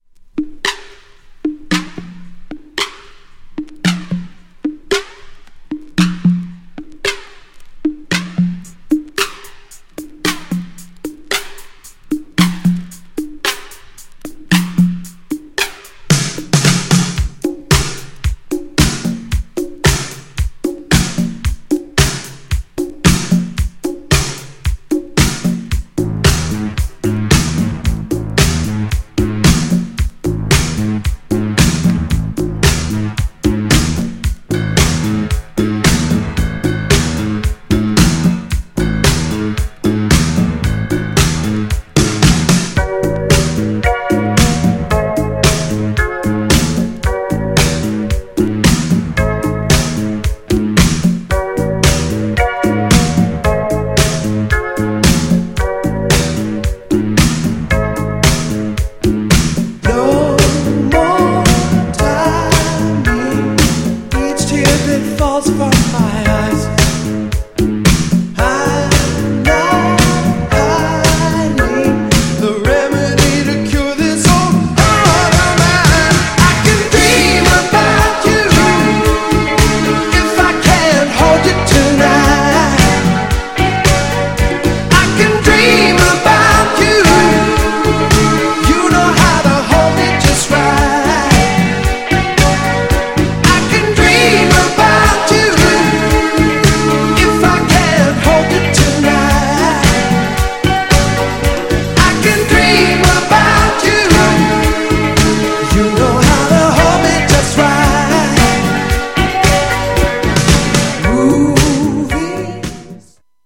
力強く歌いながら優しいダンスチューンになってるのは流石。
GENRE Dance Classic
BPM 106〜110BPM